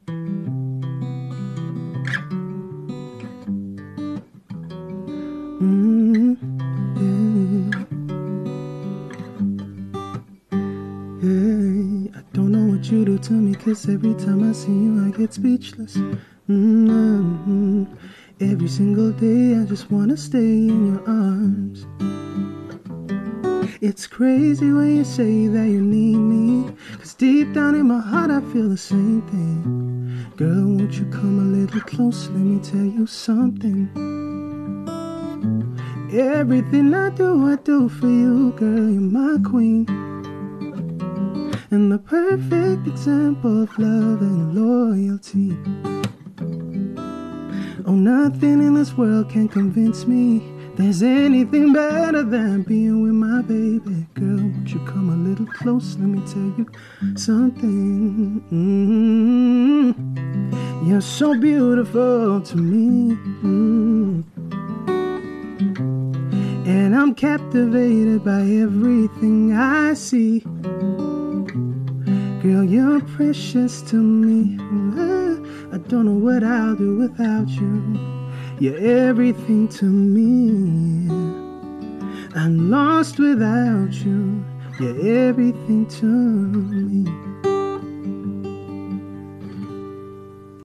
live in studio performing his original song